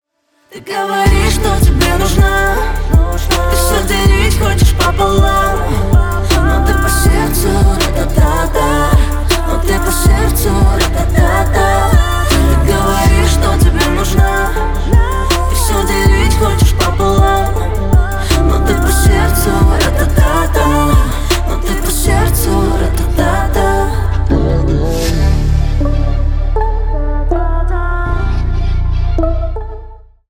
на русском на парня грустные